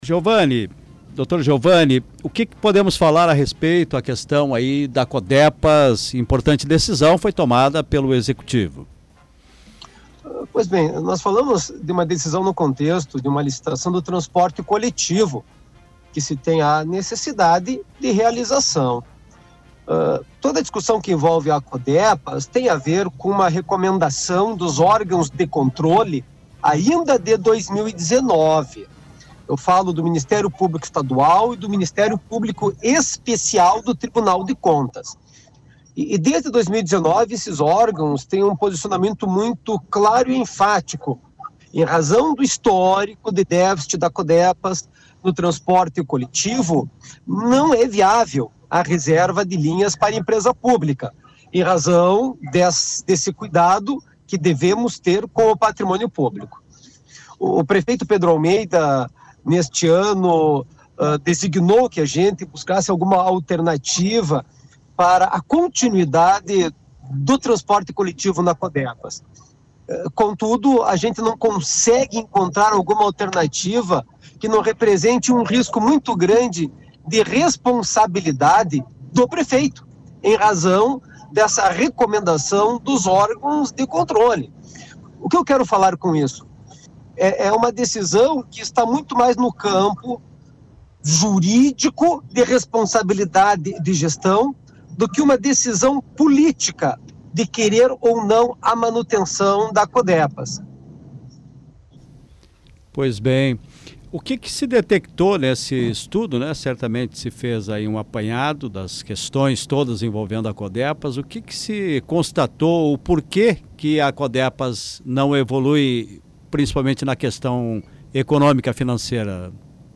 O Procurador Geral do Município, Giovani Corralo, participou do programa Comando Popular, da Rádio Planalto News (92.1), nesta segunda-feira, 18.